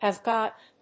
アクセントhave gòt